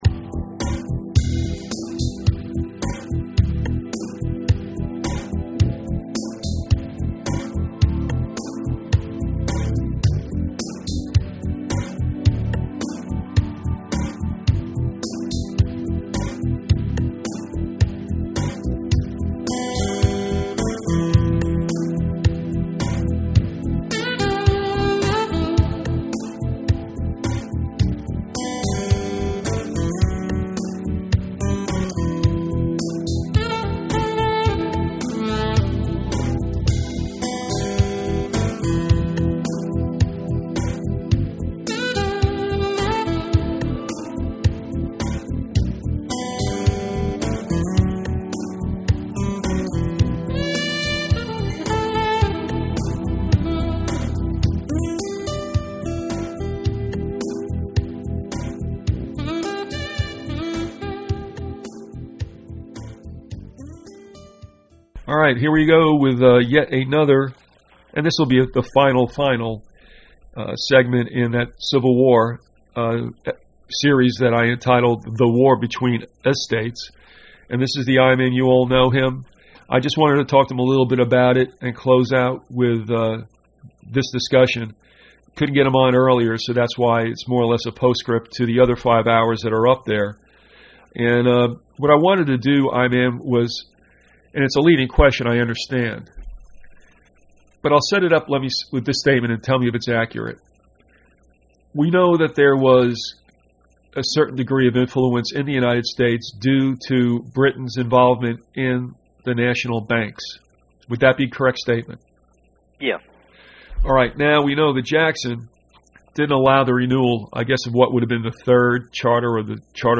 If you took notes and would like to share them with others, please send an email to the archivist and be sure to reference the title of the interview.